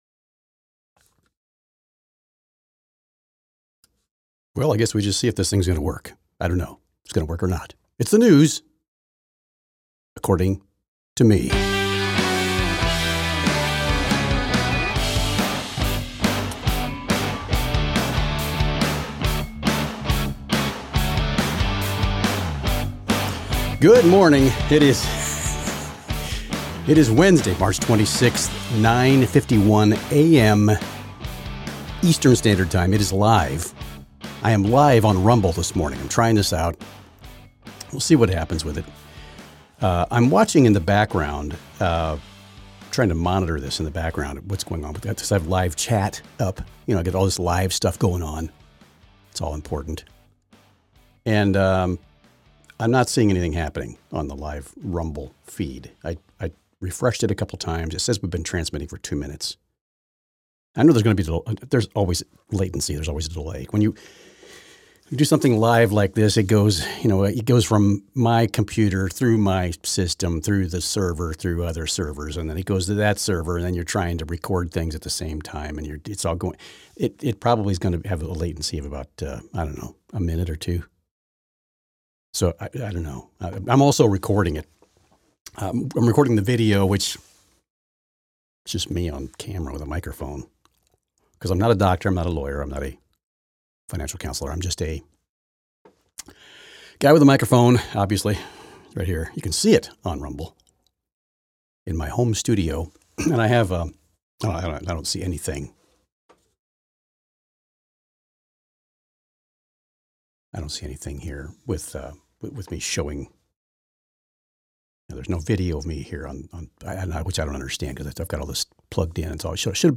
Trying this live thing on Rumble!